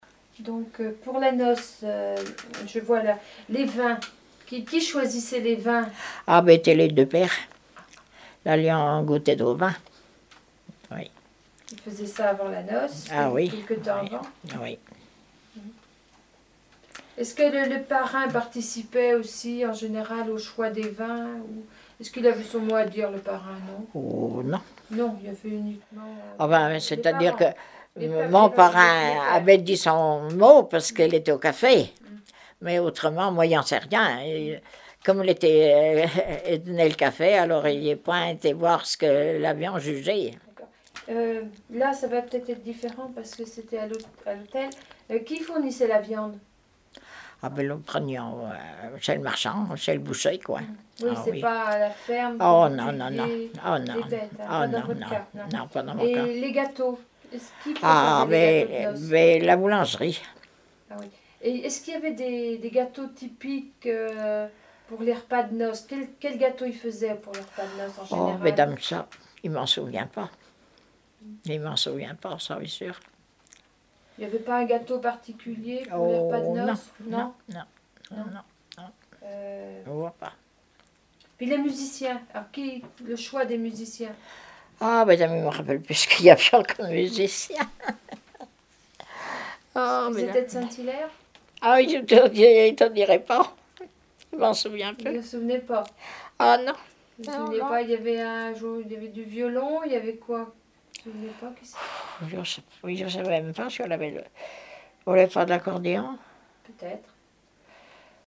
Témoignage sur la vie de l'interviewé(e)